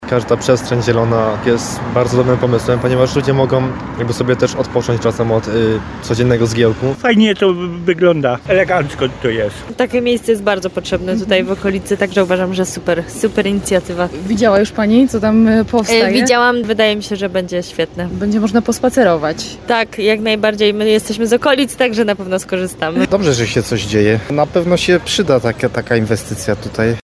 Taka inwestycja tutaj na pewno się przyda – mówią mieszkańcy i osoby odwiedzające Nawojową.
19sonda_nawojowa.mp3